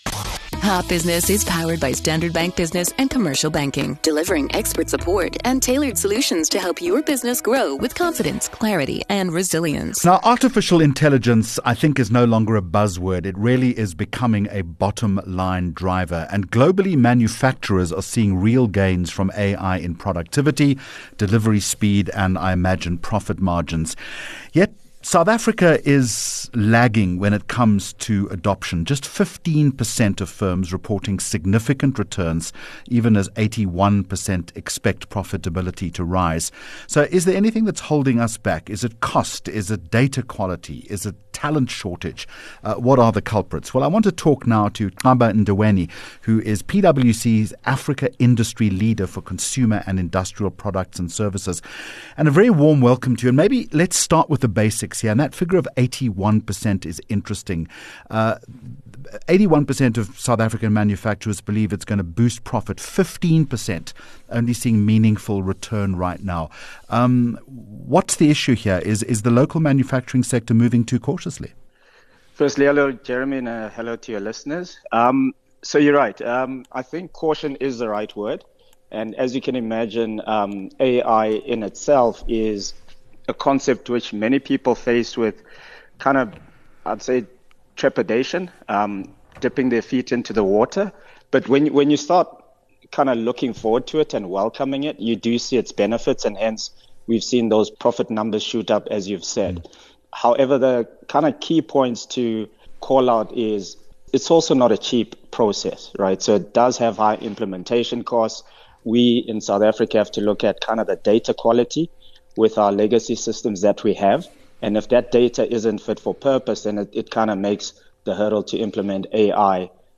23 Jul Hot Business Interview